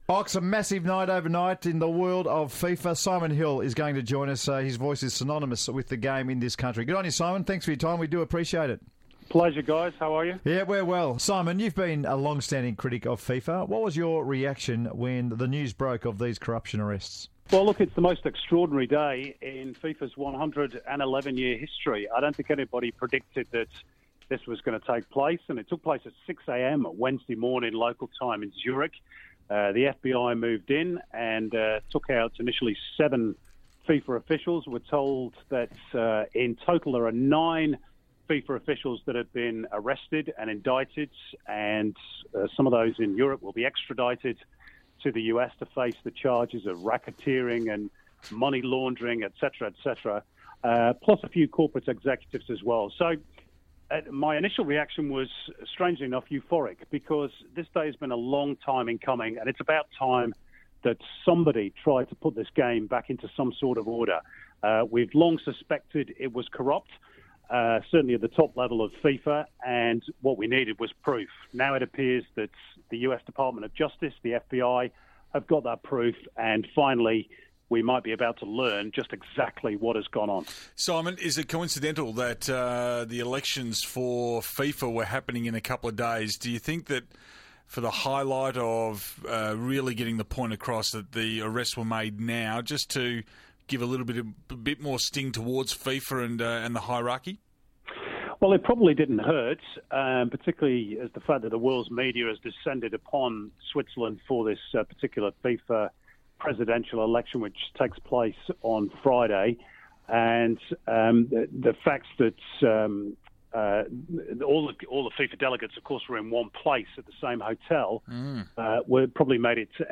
Fox Sports commentator Simon Hill speaks to The Run Home about FIFA officials being arrested on corruption allegations.